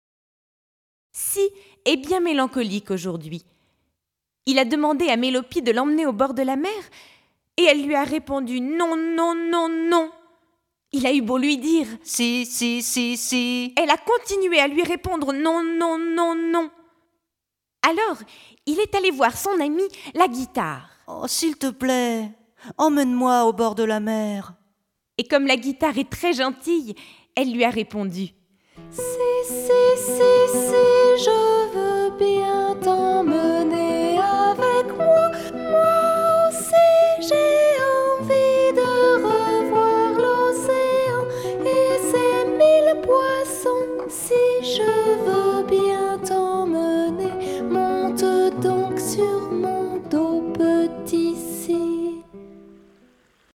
Ils chantent des comptines et comment ils sont faits. Ce sont des aventures simples dédiées aux jeunes enfants, racontées aux sons des plus célèbres musiques du répertoire classique. Les jeunes auditeurs apprennent ainsi à écouter et à reconnaitre les instruments de musique à leur son.
Extrait-cordes-pincees.mp3